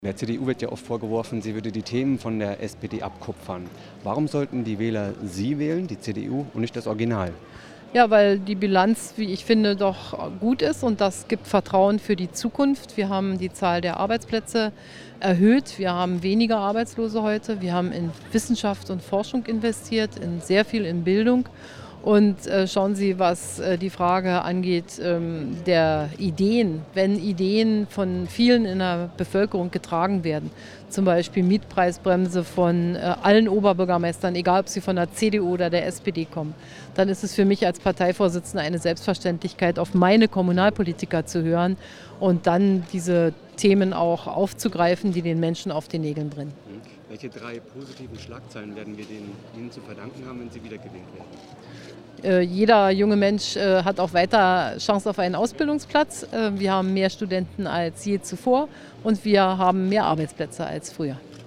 Beim Besuch der Kanzlerin in Ilmenau hatten nicht nur wir die Möglichkeit mit ihr zu sprechen, sondern auch MDR JUMP führte ein kurzes Gespräch.